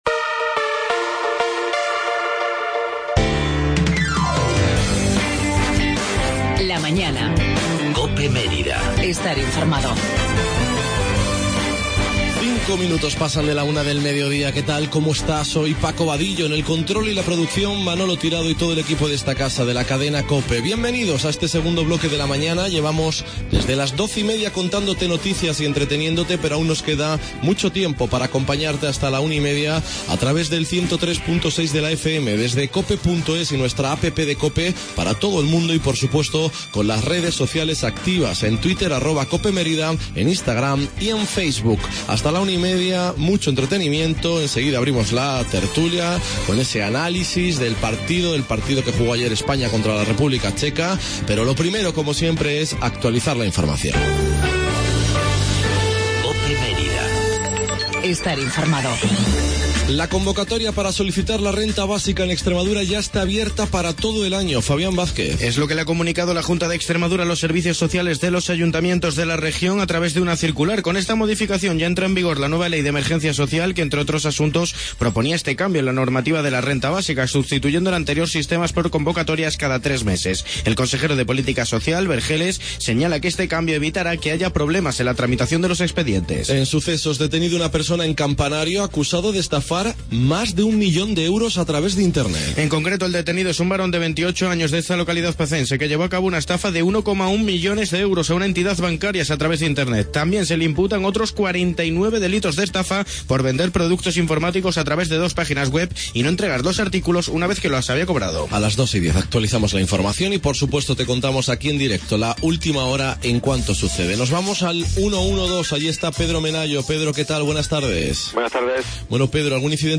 ESPECIAL TERTULIA EUROCOPE 14-06-16